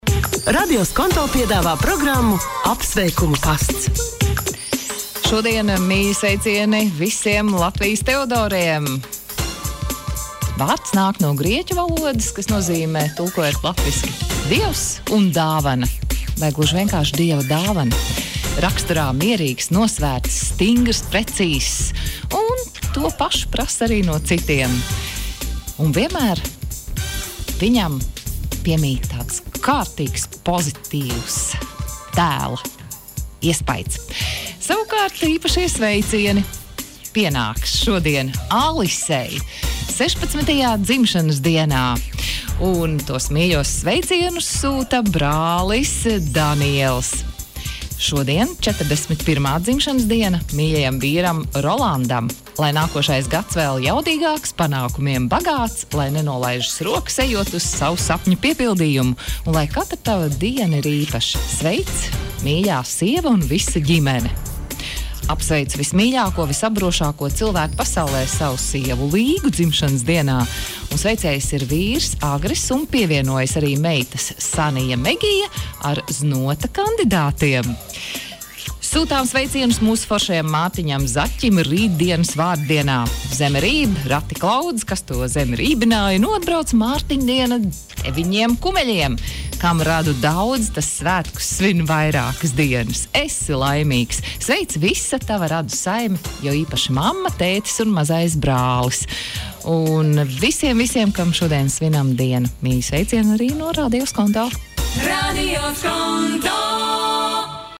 RADIO SKONTO sveic savus klausītājus katru darba dienu ap plkst. 15.30 un sestdienās ap plkst. 12.30.